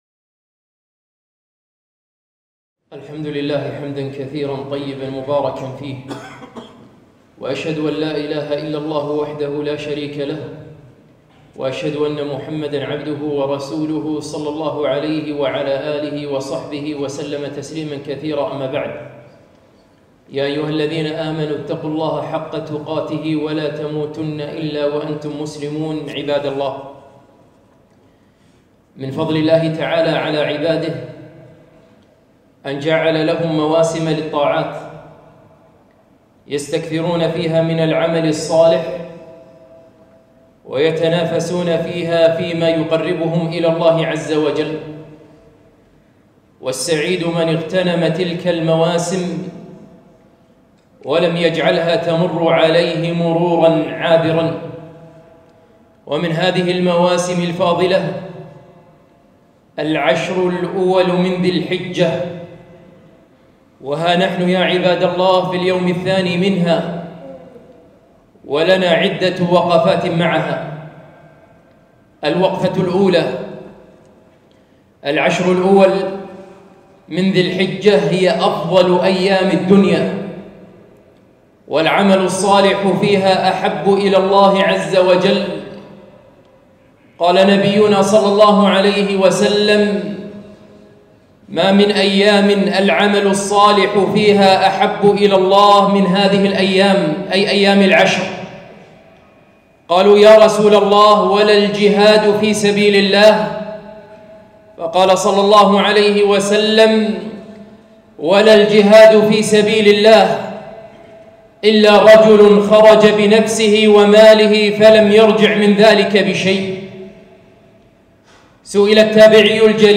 خطبة - عشر ذي الحجة